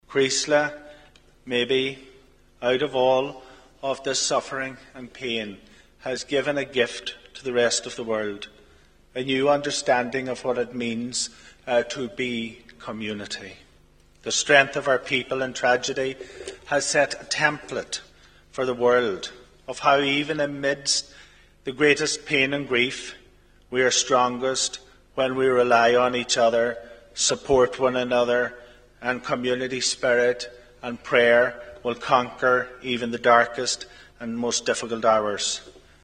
told the gathering